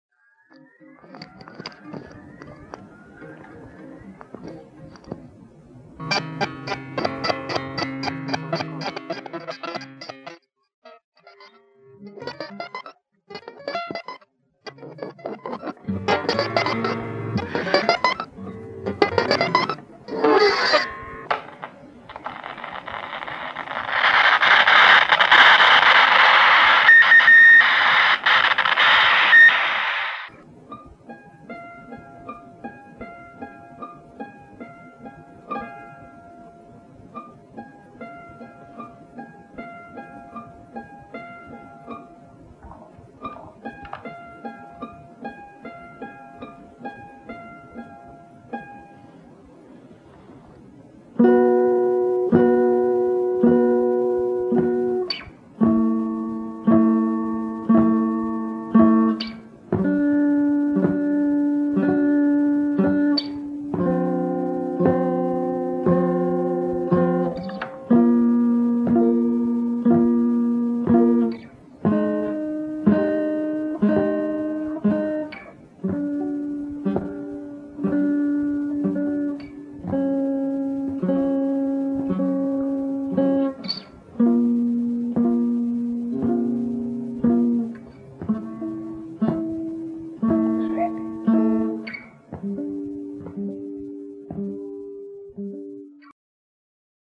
Σημείωση: Η κακή ποιότητα του ήχου δεν οφείλεται στα mp3.